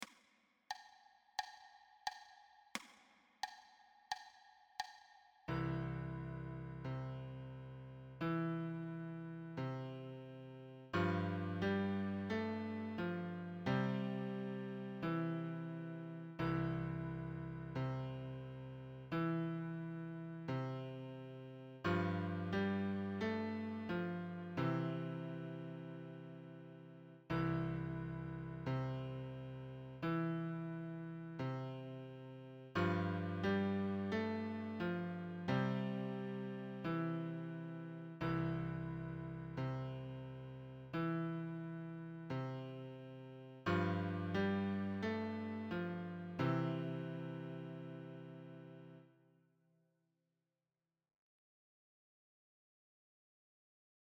Lehrerbegleitung